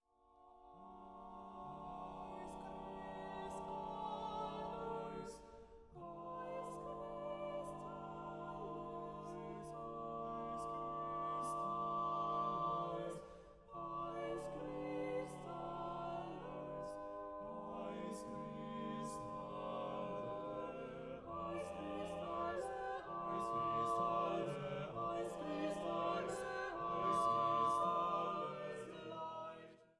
kristallklar aufgenommen im Gewandhaus zu Leipzig